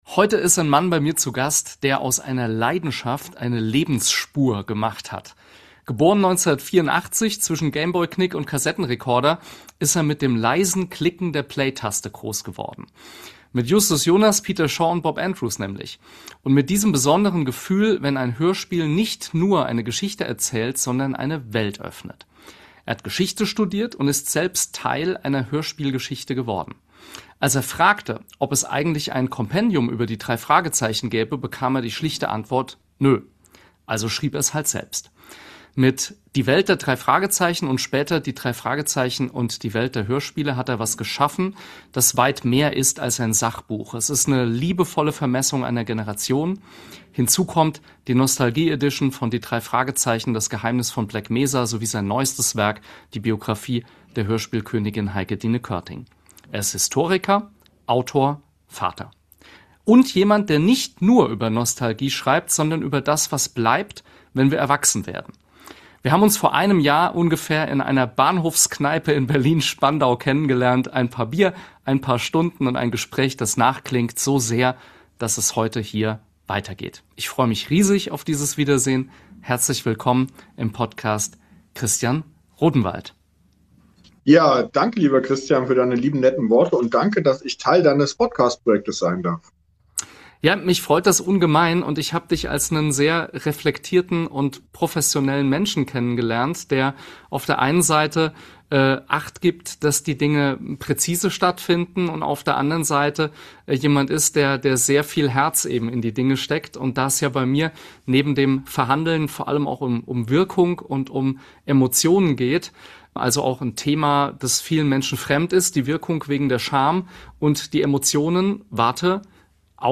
Ein Gespräch voller Tiefe, Intensität, Enthusiasmus und Optimismus.